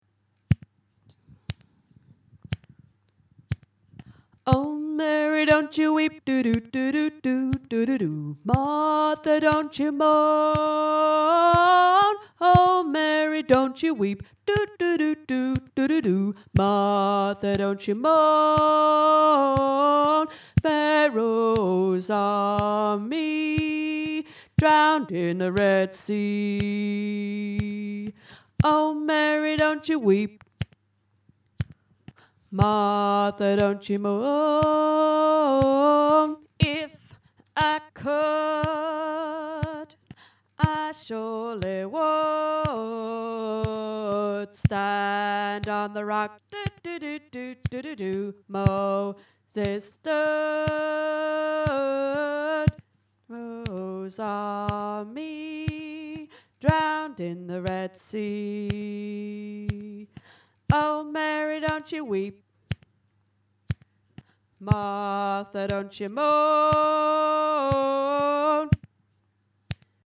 oh-mary-dont-you-weep-bass1.mp3